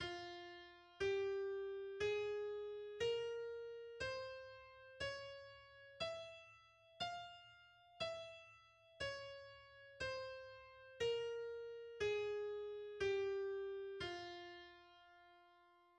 The E-sharp harmonic minor and melodic minor scales are: